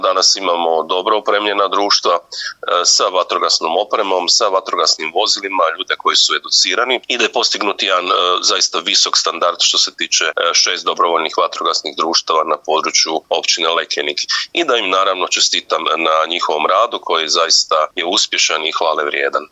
Zajedno smo prošli poplave, potrese, pandemiju, oni za zajednicu daju najviše, rekao je o vatrogascima, načelnik Općine Lekenik Ivica Perović na, nedavno održanoj Skupštini Vatrogasne zajednice ove općine.